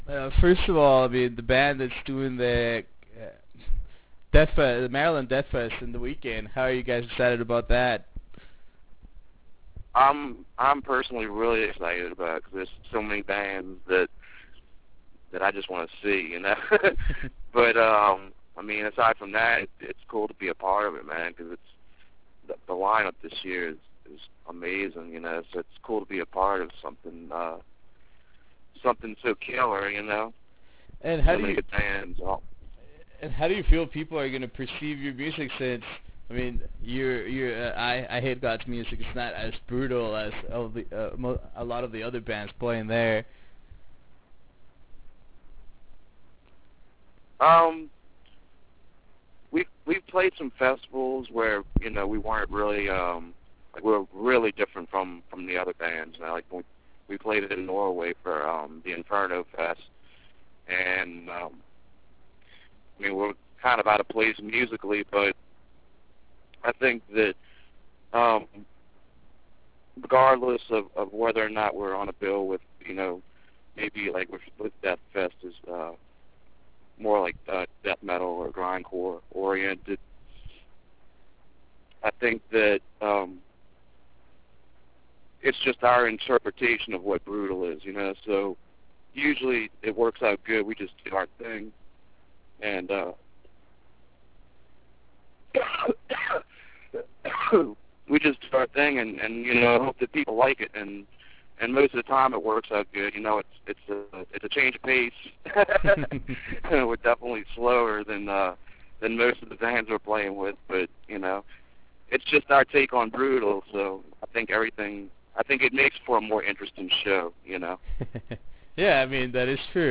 Interview with Eyehategod